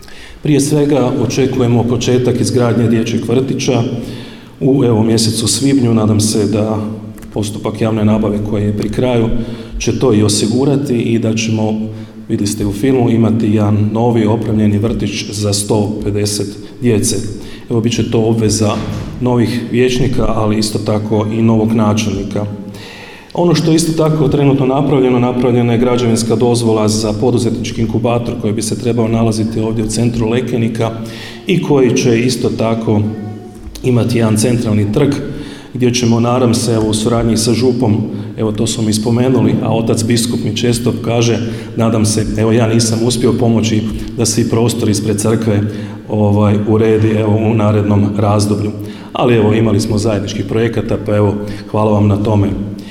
Obilježba je zaključena prigodnim programom u Društvenom domu i turističko-informativnom centru u Lekeniku, gdje su načelnik Ivica Perović i sada već bivši predsjednik Općinskog vijeća Marin Čačić dodijelili javna priznanja Općine Lekenik u 2025. godini.